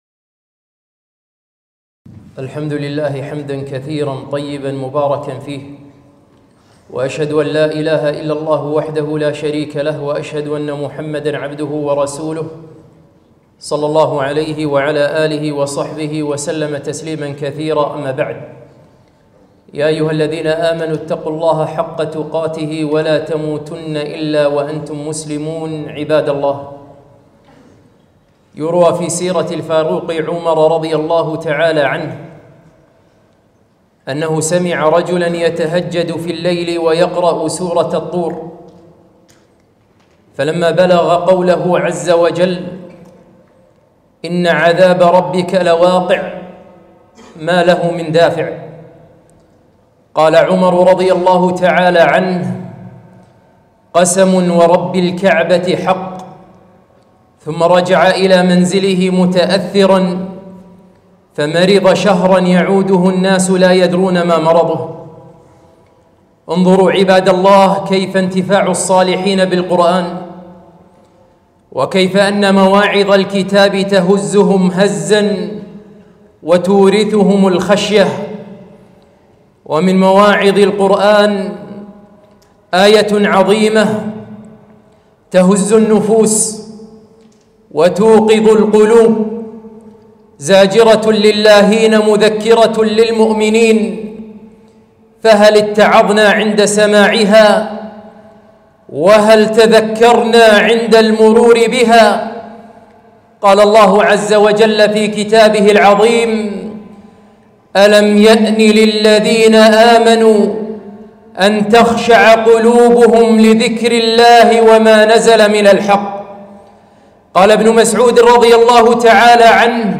خطبة - قسوة القلب ودخول شعبان